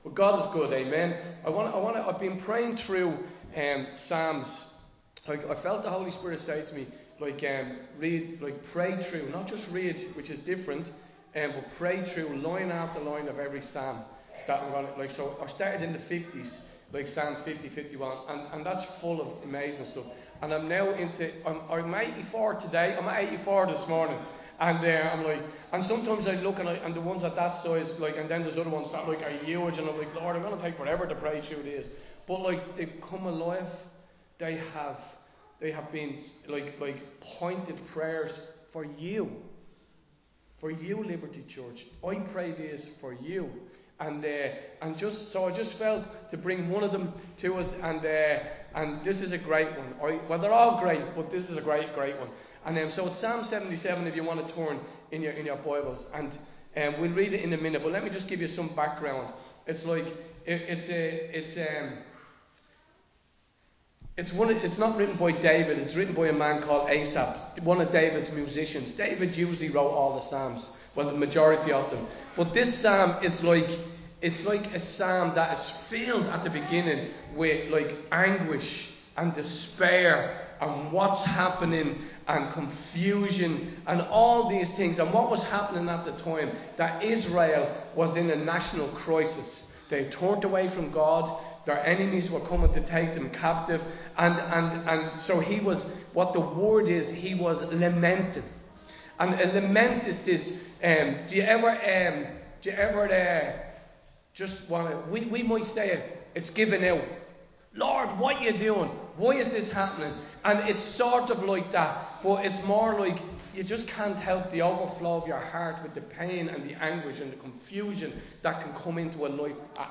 Recorded live in Liberty Church on 28 September 2025